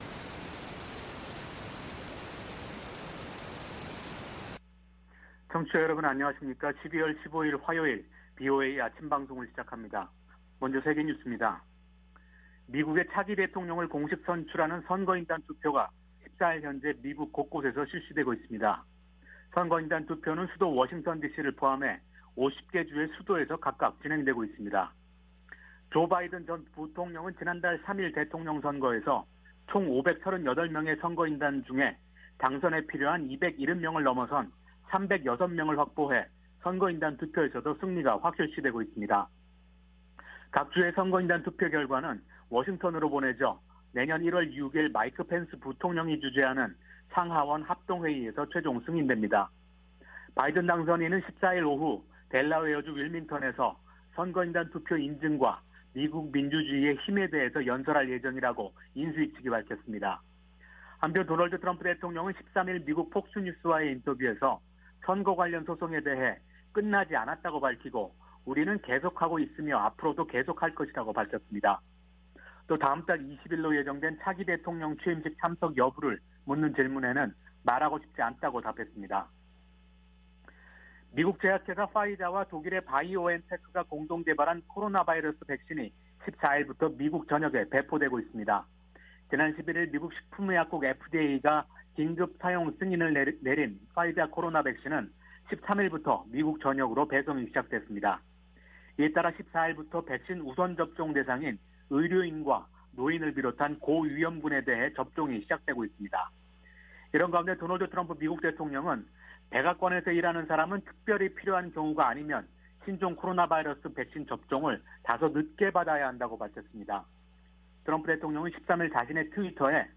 생방송 여기는 워싱턴입니다
세계 뉴스와 함께 미국의 모든 것을 소개하는 '생방송 여기는 워싱턴입니다', 아침 방송입니다.